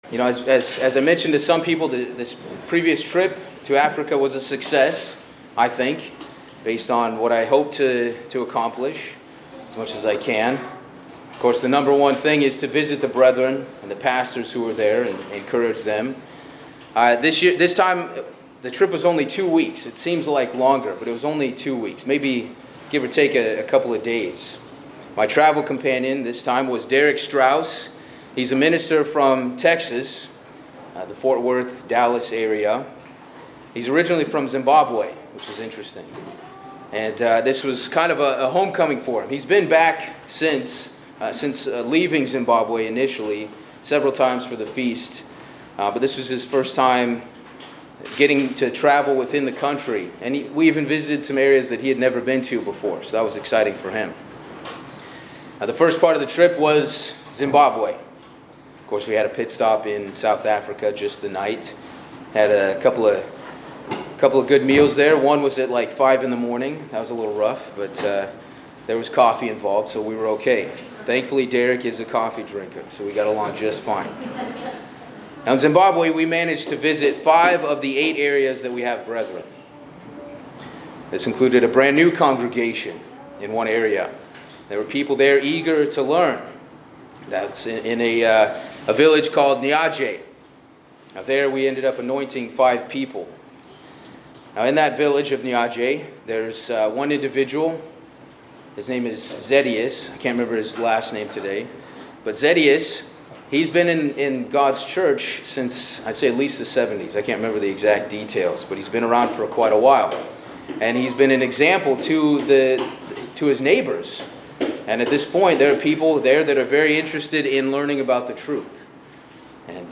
This detailed sermon recounts a mission trip to Africa, focusing on visits to brethren and pastors in Zimbabwe, Malawi, and South Africa, combined with a deep theological exploration of the concept of love from a biblical perspective. The speaker reflects on the experiences of fellowship, baptisms, and congregational growth, before delving into the spiritual nature of love as described in the New Testament scriptures, reflecting on the significance of God’s love as the most important aspect of the Christian life.